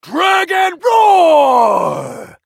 draco_kill_vo_09.ogg